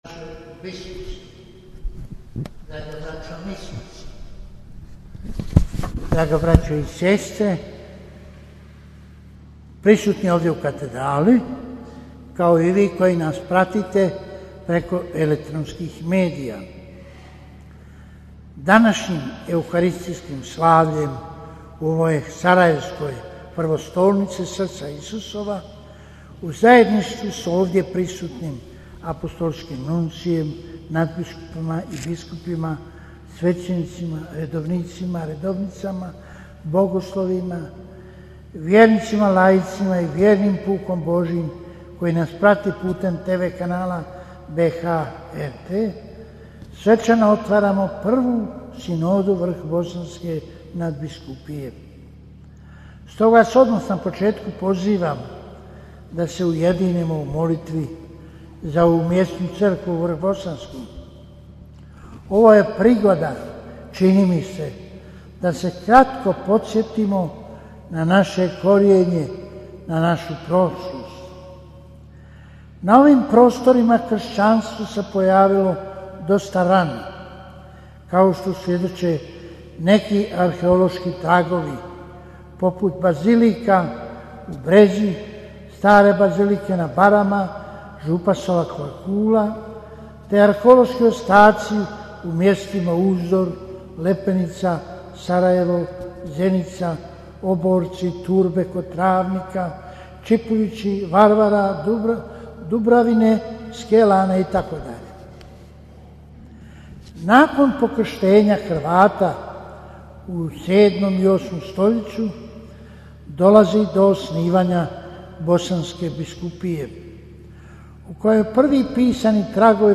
AUDIO: PROPOVIJED KARDINALA PULJIĆA NA SVEČANOM OTVORENJU PRVE SINODE VRHBOSANSKE NADBISKUPIJE - BANJOLUČKA BISKUPIJA
U subotu, 11. rujna 2021. svečanom euharistijom uz izravan prijenos BHT 1 u sarajevskoj katedrali Srca Isusova svečano je otvorena Prva sinode Vrhbosanske nadbiskupije. Misu je predslavio predsjednik Sinode kardinal Vinko Puljić, nadbiskup metropolit vrhbosanski koji je tom prigodom uputio svoju homiliju: